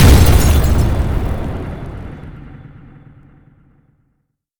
sfx_explosion_1_temporary.wav